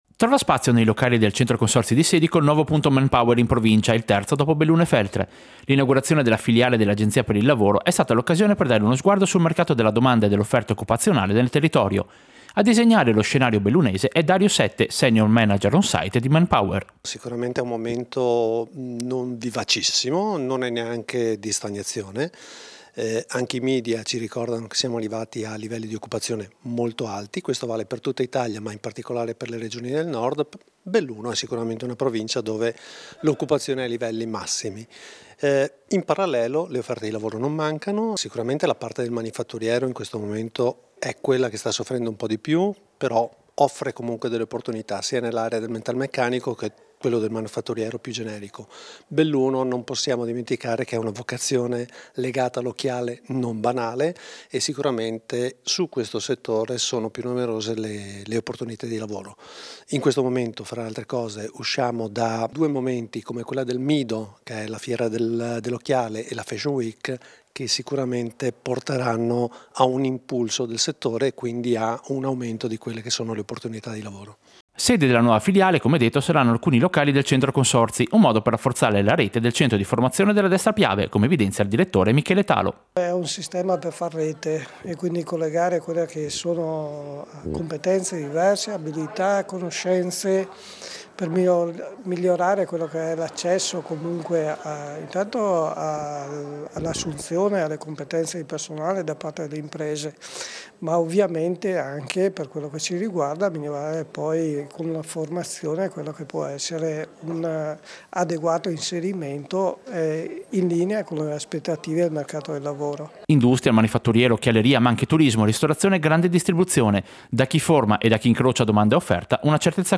Servizio-Sportello-Manpower-Centro-Consorzi-Sedico.mp3